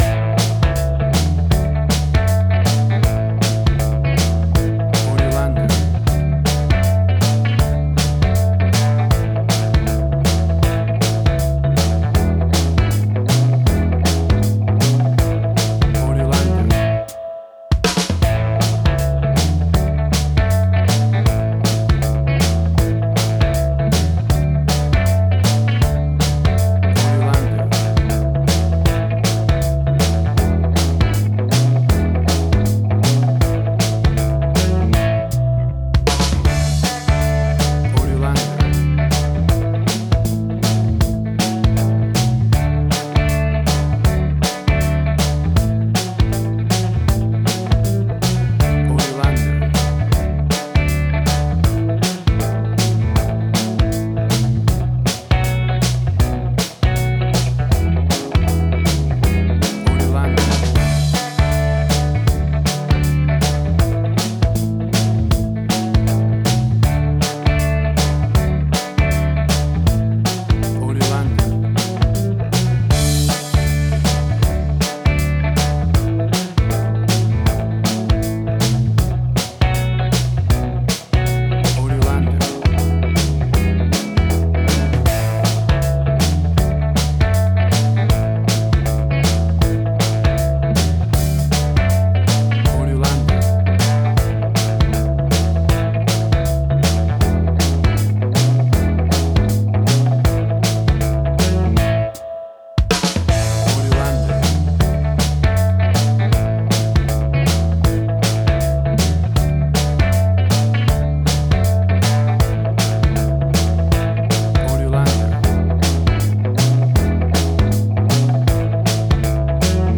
Tempo (BPM): 79